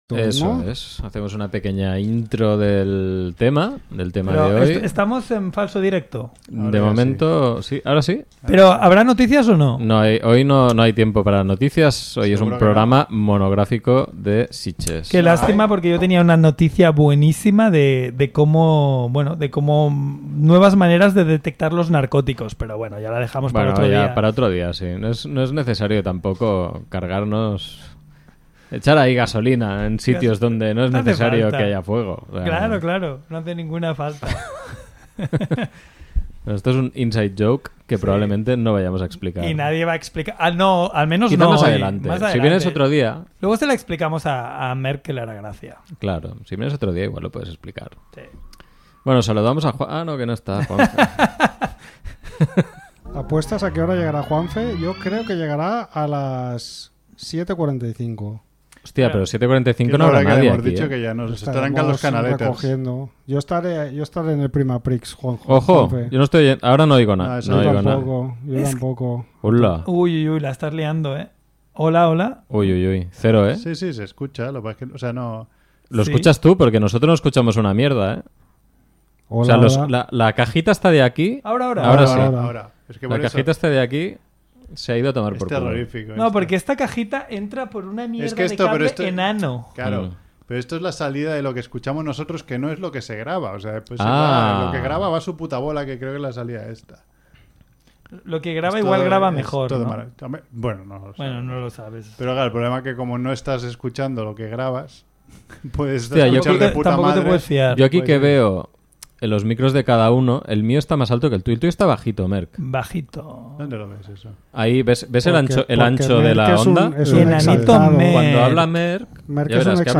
Esta vez lo hacemos por teléfono por causas mayores, con sus consecuentes problemillas, pero como siempre muy interesante para todos aquellos amantes del cine de género, terror y fantástico.